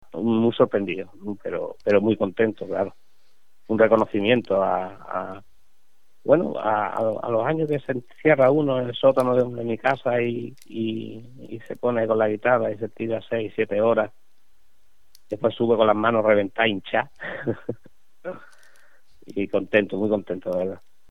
con espontaneidad formato MP3 audio(0,40 MB).